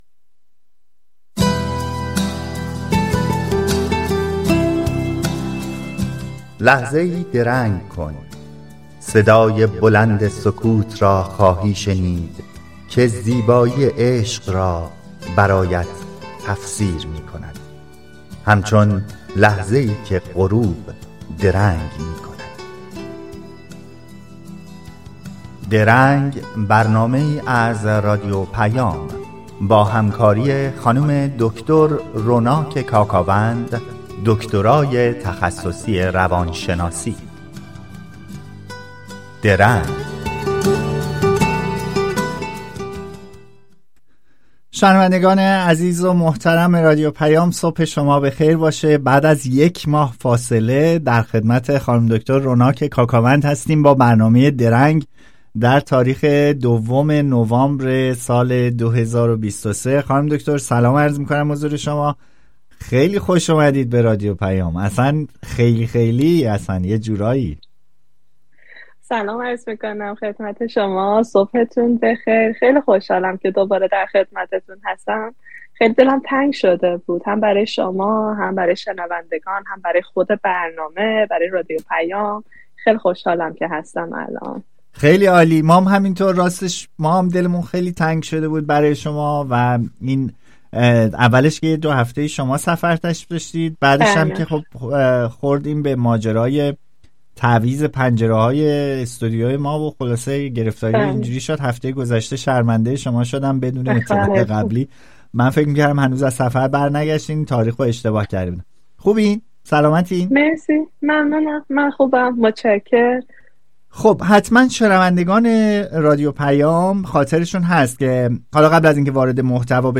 شما در این صفحه می‌توانید به برنامهٔ «درنگ» که هر هفته به‌صورت زنده از رادیو پیام گوتنبرگ سوئد پخش می‌شود، گوش دهید. این برنامه با هدف پرداختن به موضوعات متنوع اجتماعی، روانشناختی و فرهنگی تهیه و ارائه می‌شود.